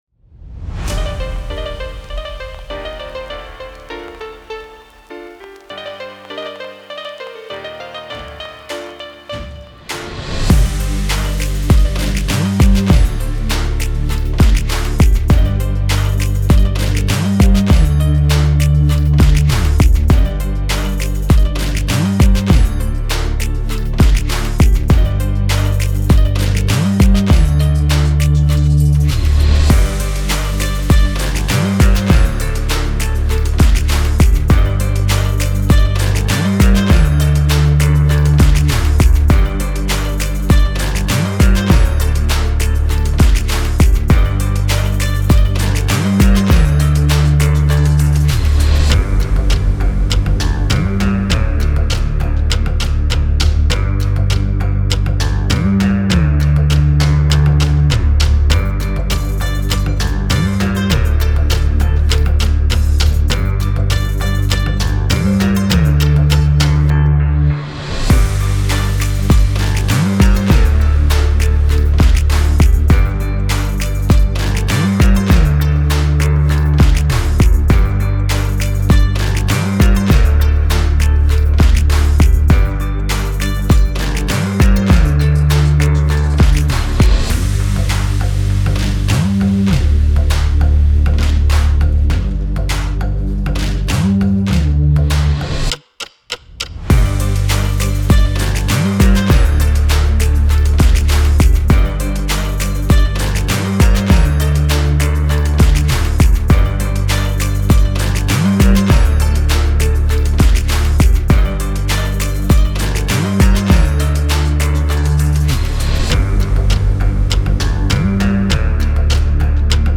MUSIC INSTRUMENTALS - WAV FILES
Rap Freestyle Type Beat | Hard Boom Bap x Trap Vibes